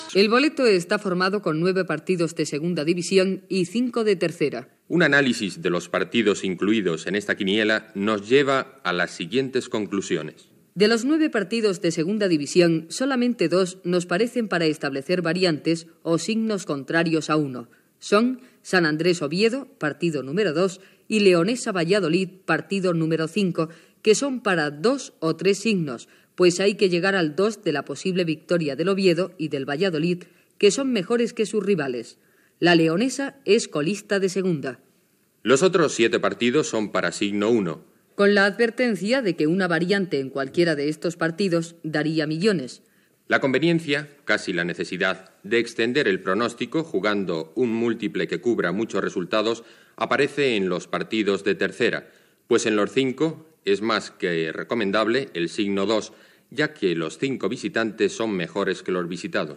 Diàleg ficcionat entre un apostant, don Celso, i el dependent d'un despatx d'administració de travesses, Ceferino.